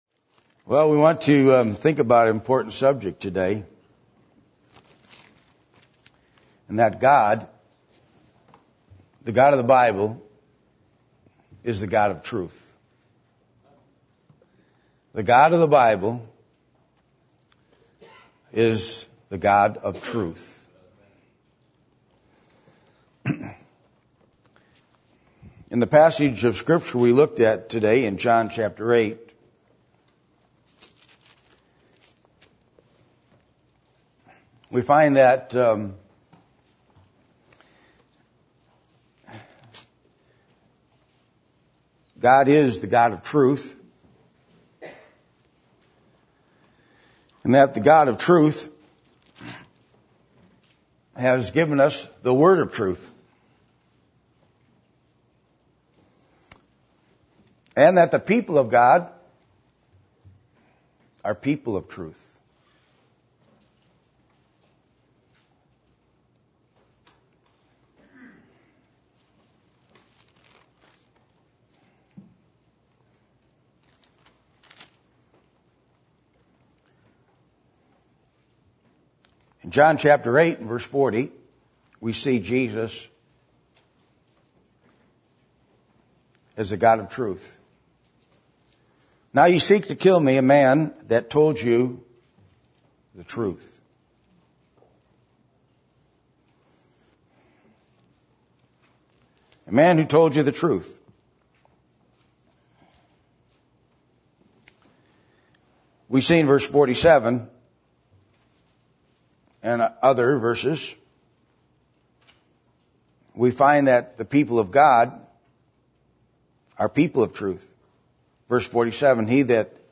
John 8:30-59 Service Type: Sunday Morning %todo_render% « The Need For Spiritual Wisdom Unrepentant